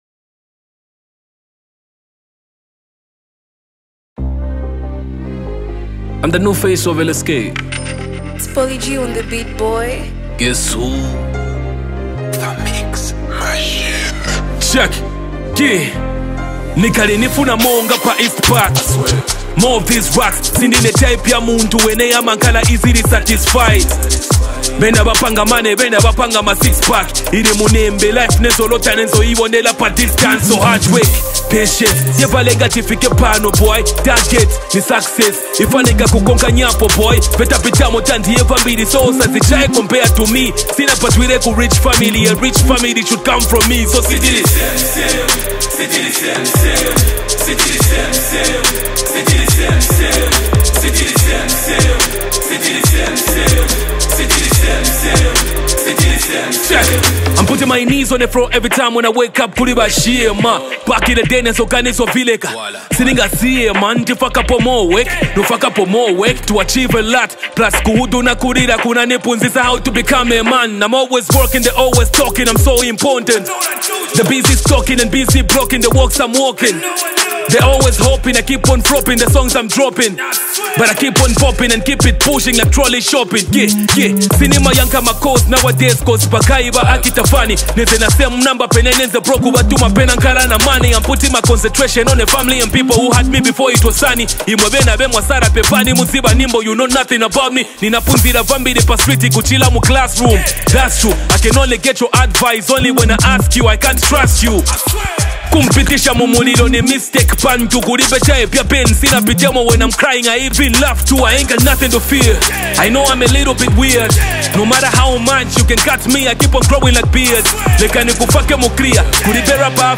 hip-hop and Afro-fusion
an emotional yet energetic sound